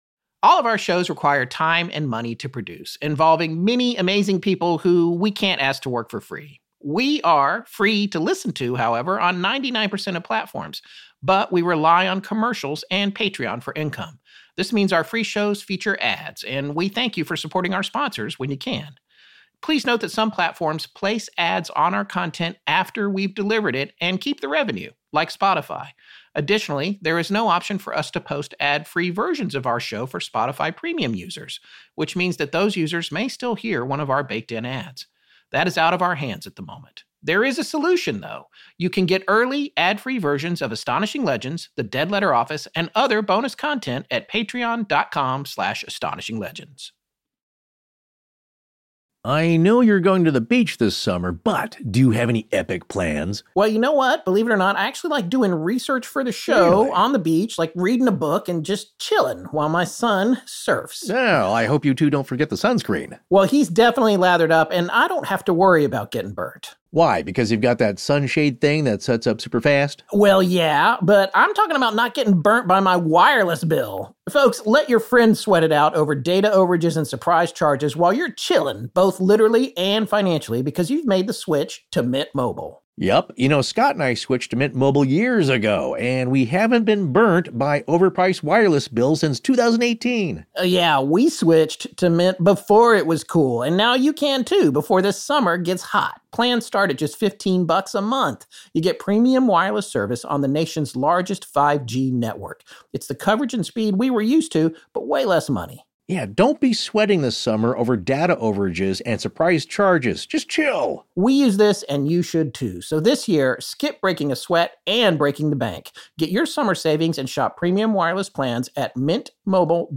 read a dead letter from a woman with ties to the Otoe-Missouria tribe in Oklahoma. She recounts a terrifying experience driving on Highway 60 at night when a dark fog hit the truck she was in, causing a flat tire.